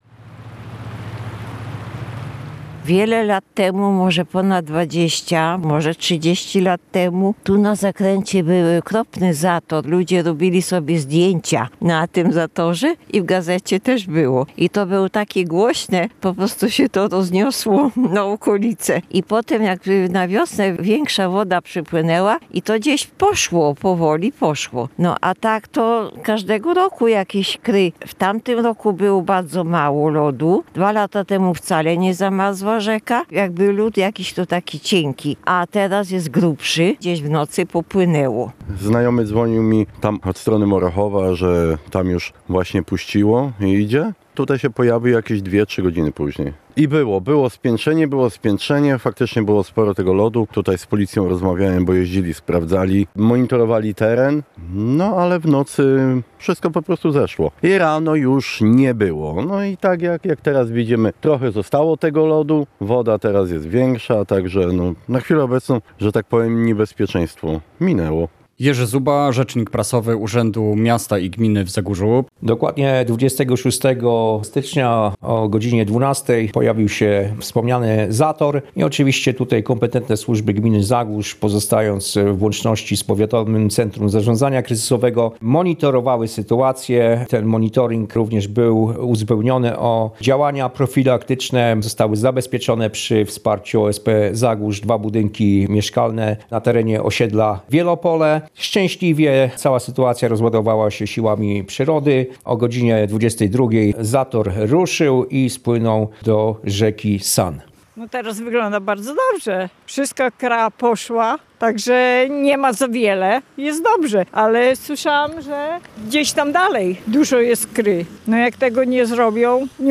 Relacje reporterskie • Zator lodowy, który pojawił się w ostatnich dniach na rzece Osława w Zagórzu, ustąpił samoistnie. Służby prewencyjnie zabezpieczały teren, jednak nie doszło do podtopień ani strat.